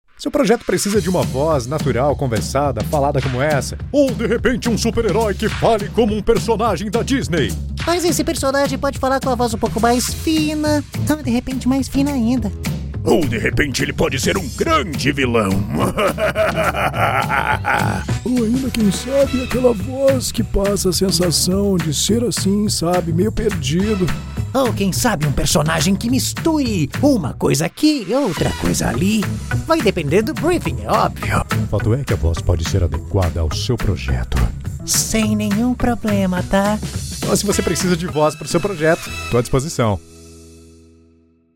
Impersonations
BassDeepLowVery Low
FriendlyWarmConversationalDarkCharming